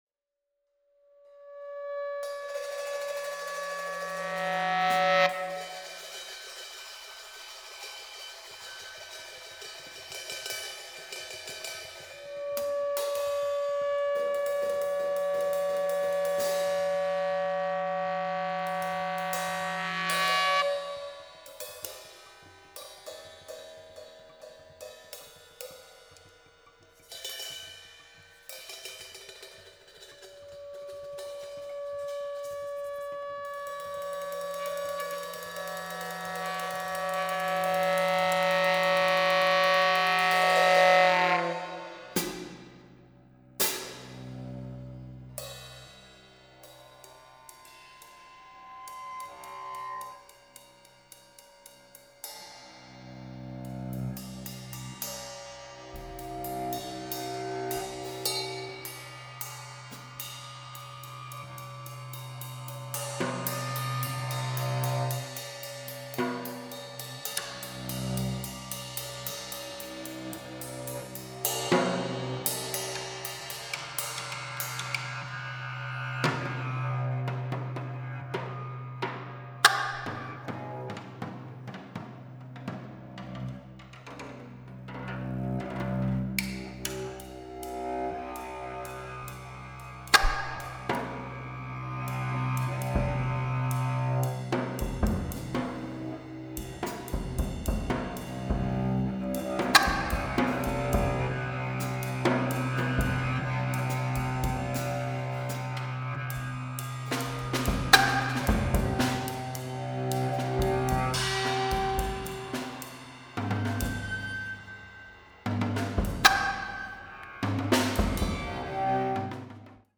cello
alto sax